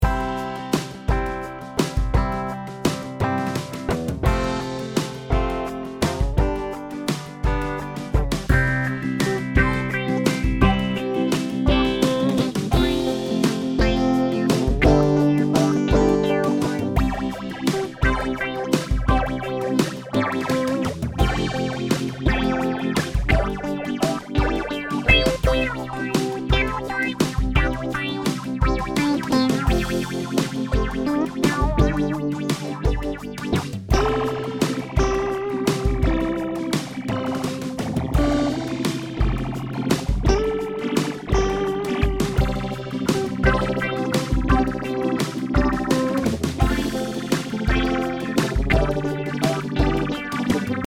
The Phasor II provides all the classic swirl in a pedalboard-friendly size and powered by industry-standard 9V DC.
• 6 stage phasing allows for the creamy and classic Mu-Tron sound
• 4 stage phasing works well with saturated signal
Sound Demo:
PhasorII_Demo.mp3